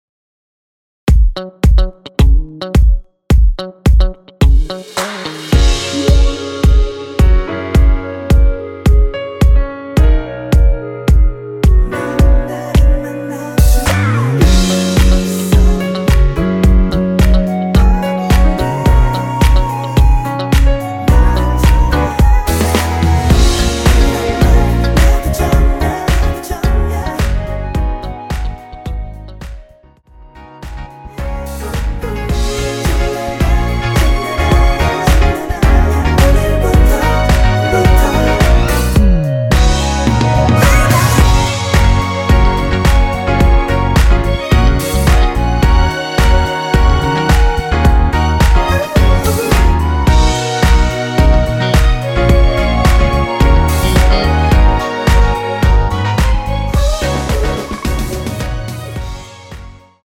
원키에서(+2)올린 멜로디와 코러스 포함된 MR입니다.(미리듣기 확인)
F#
앞부분30초, 뒷부분30초씩 편집해서 올려 드리고 있습니다.
중간에 음이 끈어지고 다시 나오는 이유는